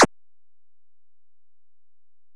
shoot.wav